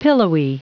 Prononciation du mot pillowy en anglais (fichier audio)
Prononciation du mot : pillowy
pillowy.wav